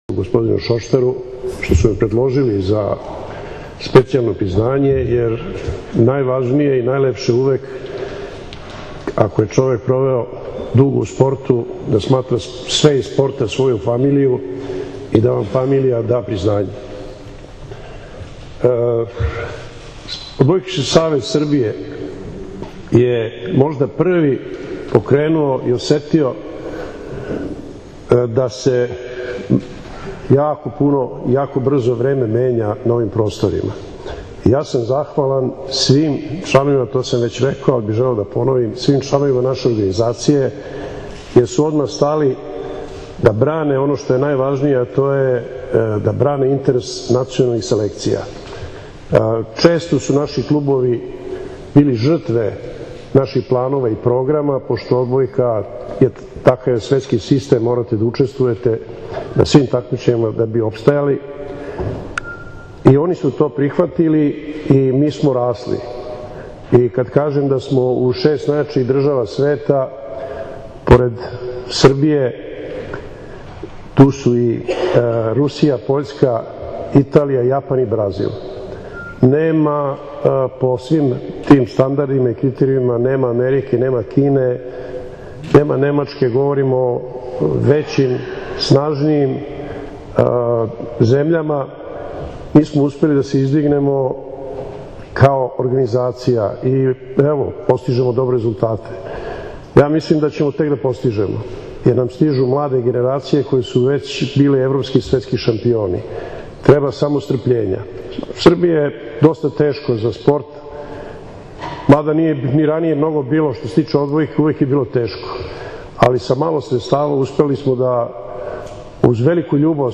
OBRAĆANJE